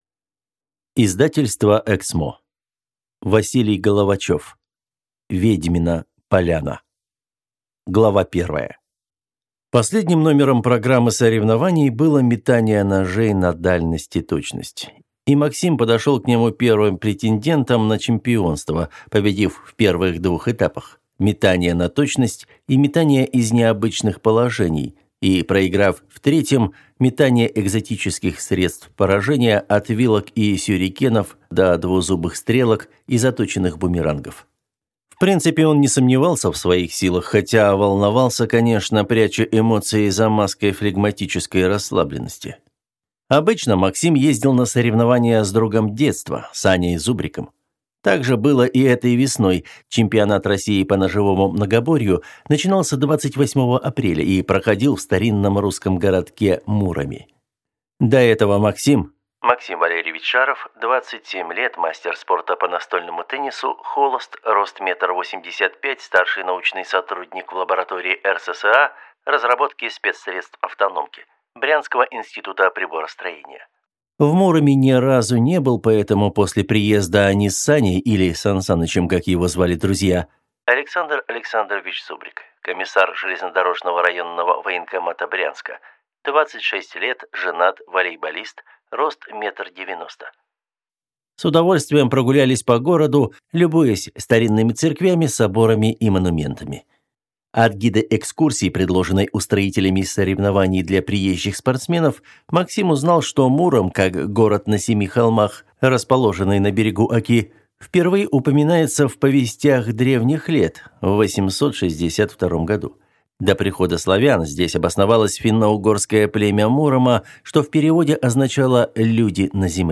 Аудиокнига Ведьмина поляна | Библиотека аудиокниг